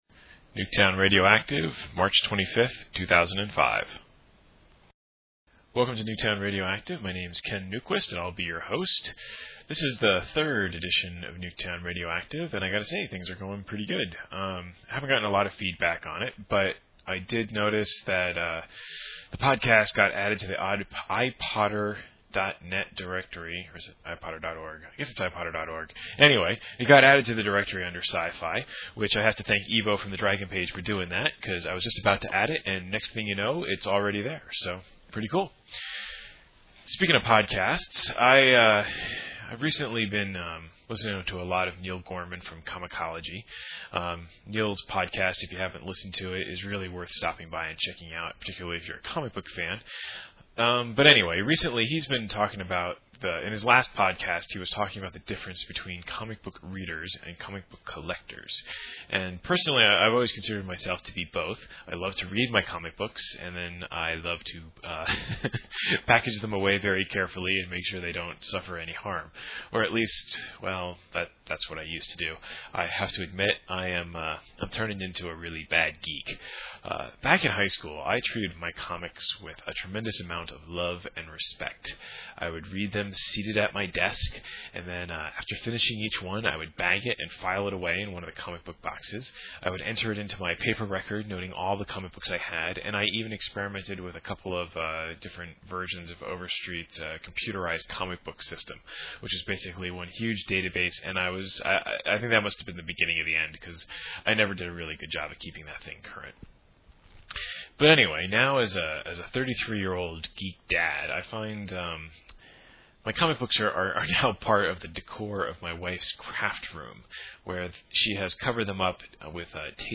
This time around I eschew the soundclips and focus on the words (mainly because GarageBand didn’t want to import them, and I didn’t want to fight with it). I spend a fair amount of time talking about comic books — what I read, what I do with them after I read them, frustrations with Marvel’s and DC’s web sites — before taking a detour into a story about my gaming group’s notorious “Great Salsa Incident”. I also discuss the podcasts I like (something of a recap for regular NT readers, but there’s some new stuff) and round it out with some thoughts on being a Geezer Gamer.
I think the audio quality is still acceptable, but let me know.